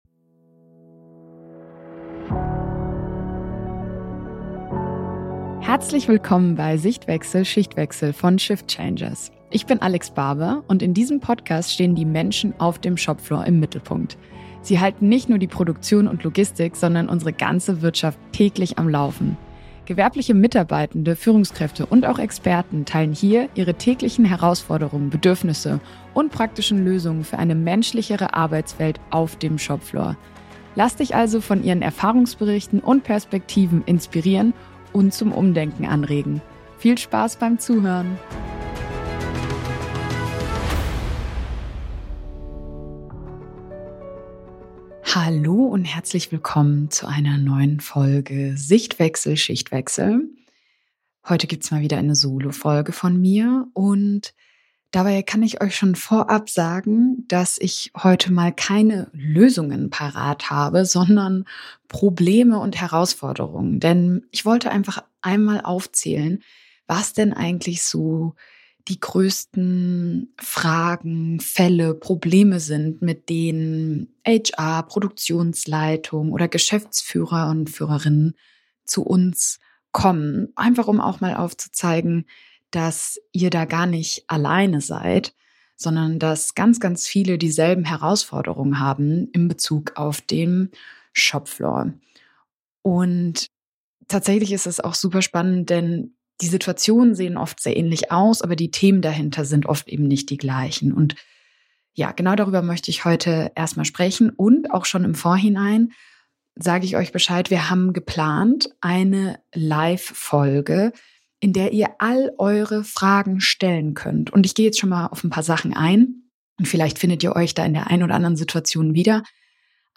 In dieser Solo-Folge teile ich die häufigsten Herausforderungen, mit denen HR-Verantwortliche, Produktionsleitungen und Geschäftsführende aktuell zu Shift Changers kommen...